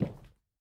stepping sounds
Parquet_Floor_Mono_02.wav